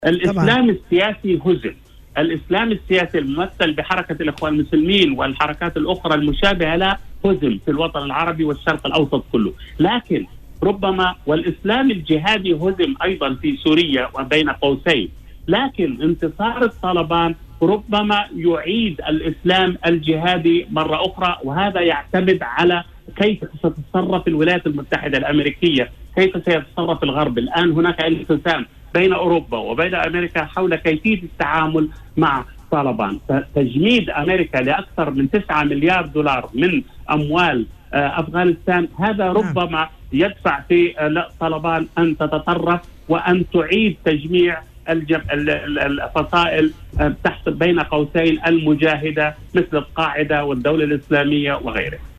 وأضاف في مداخلة له اليوم على "الجوهرة أف أم" أن ذلك يعتمد على كيفية تصرّف الولايات المتحدة الأمريكية ودول الغرب عامة وتعاملهما مع حركة طالبان.